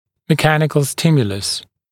[mɪ’kænɪkl ‘stɪmjələs][ми’кэникл ‘стимйэлэс]механический стимул